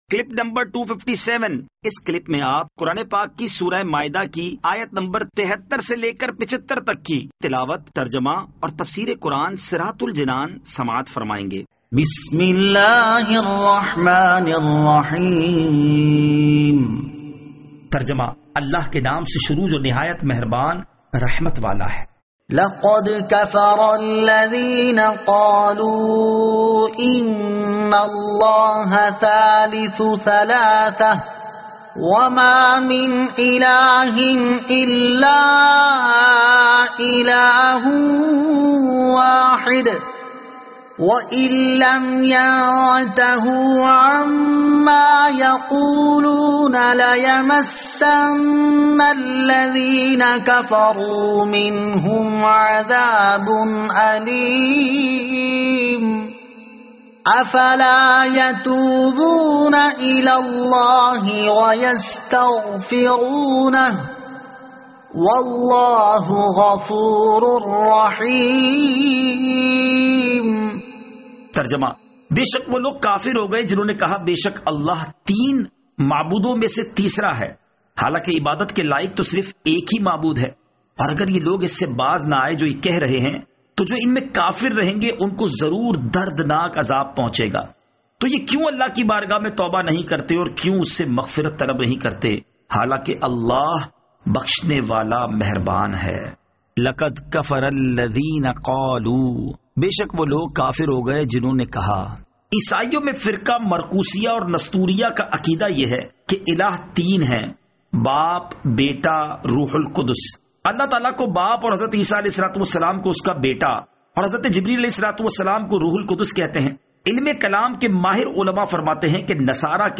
Surah Al-Maidah Ayat 73 To 75 Tilawat , Tarjama , Tafseer